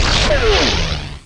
TAKEOFF1.mp3